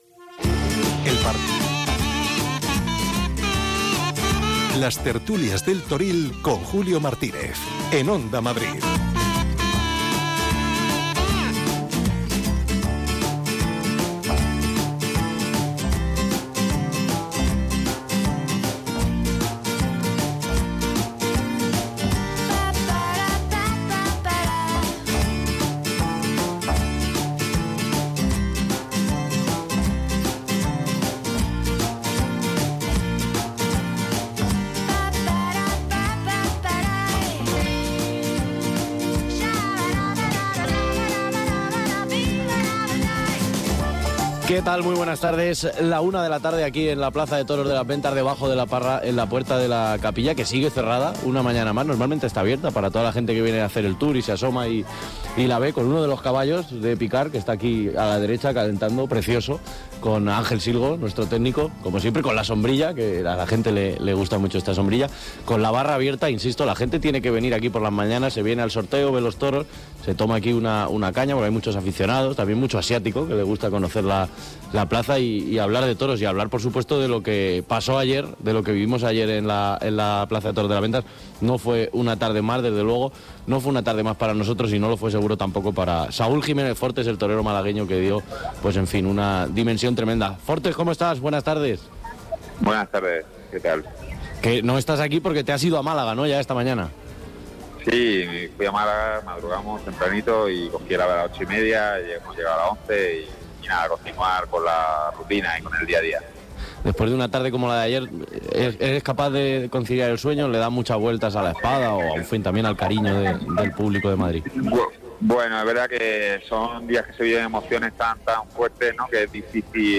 entrevistará a toreros, ganaderos y expertos del sector cada día desde Las Ventas.